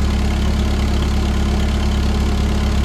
build.wav